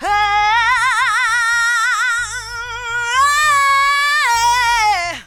YEAH YEAH.wav